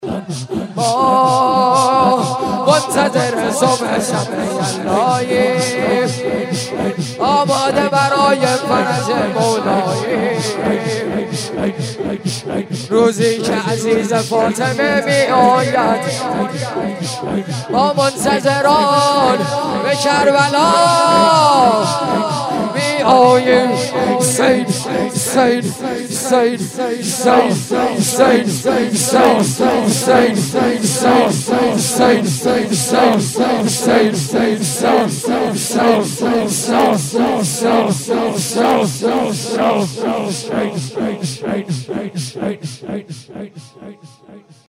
رجز و ذکر